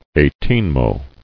[eight·een·mo]